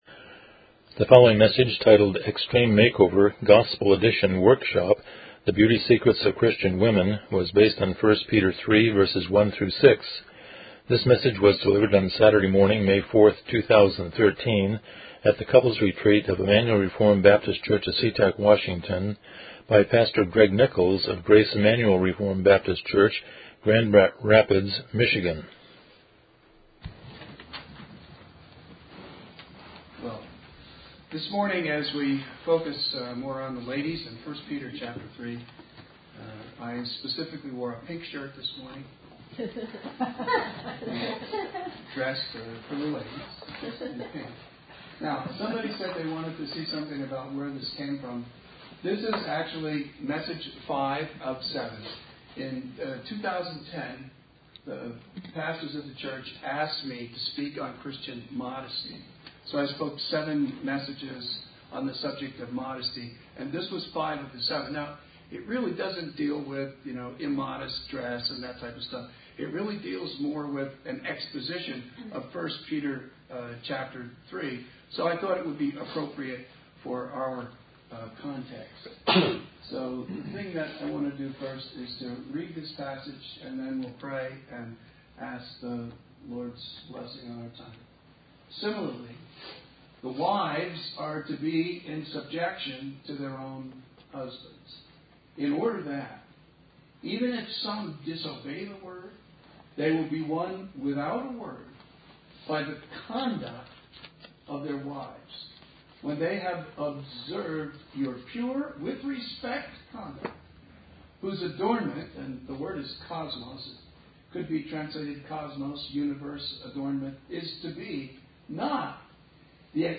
Passage: 1 Peter 3:1-6 Service Type: Special Event